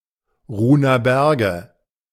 German pronunciation) are a terminal moraine ridge up to 176.8 m above sea level (NHN), which lies on either side of the border between the German states of Brandenburg and Mecklenburg-Vorpommern near Parchim.
De-Ruhner_Berge.ogg.mp3